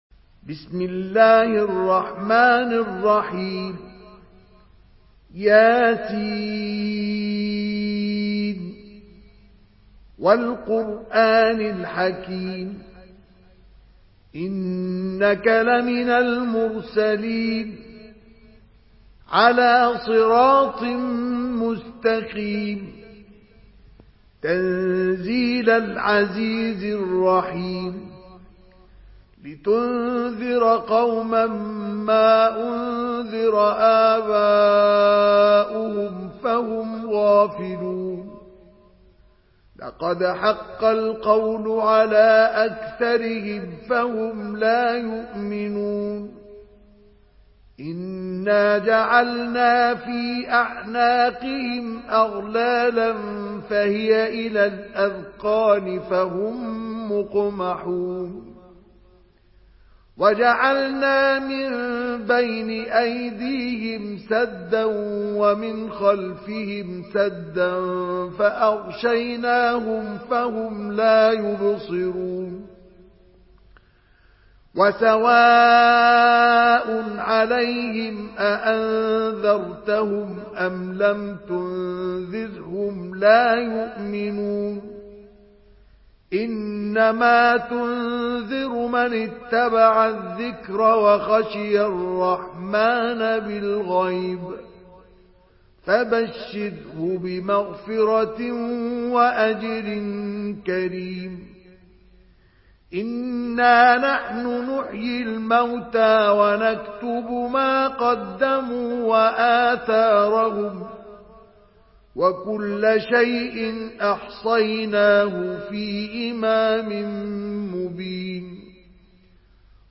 Surah ইয়াসীন MP3 by Mustafa Ismail in Hafs An Asim narration.
Murattal